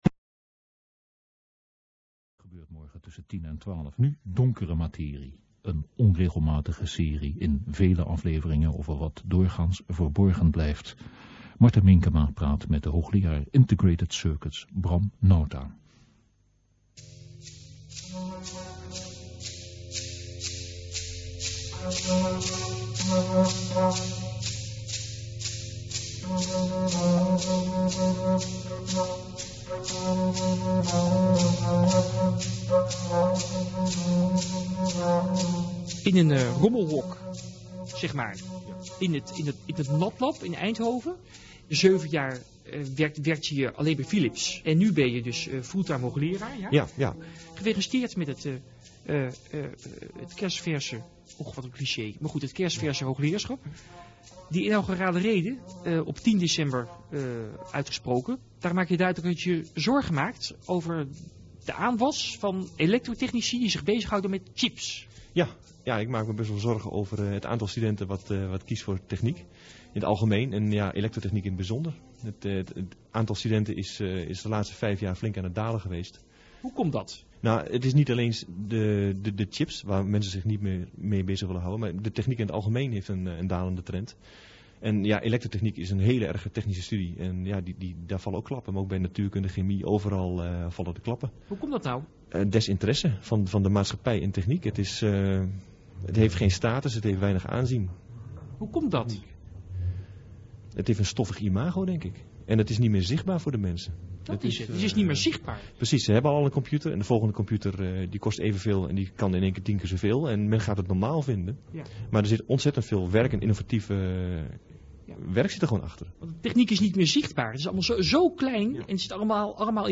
Radio Interview Februari 1999